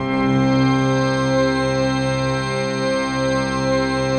PAD 46-3.wav